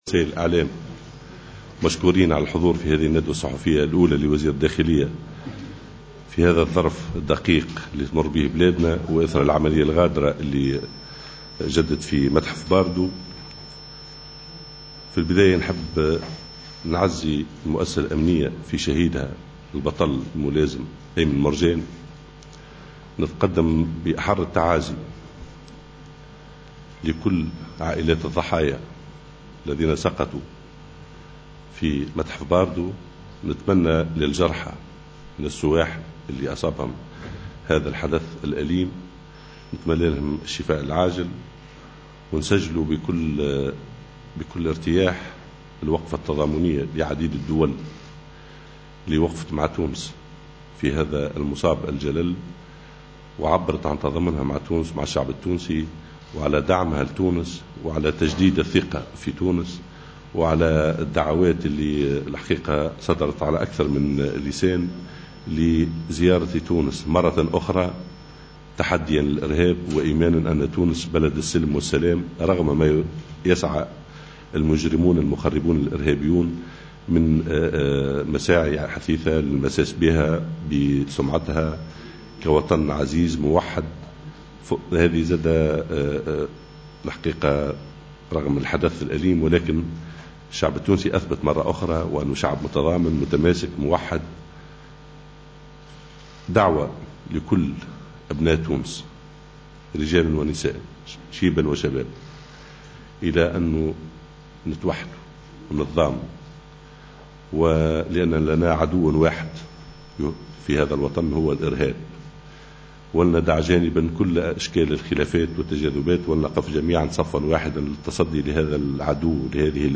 الندوة الصحفية كاملة تحميل المشاركة علي